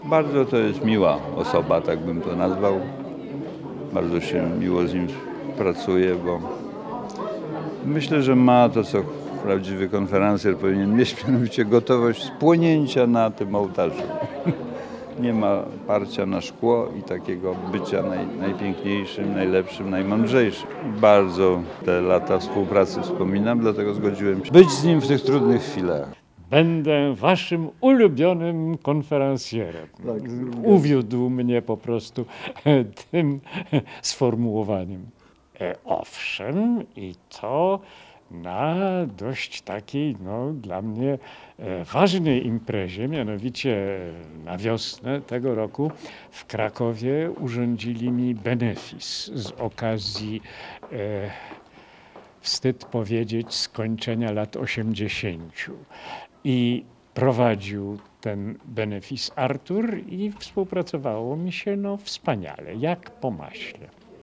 Swoimi wspomnieniami dotyczącymi Artura Andrusa postanowili podzielić się także Jacek Fedorowicz oraz Andrzej Poniedzielski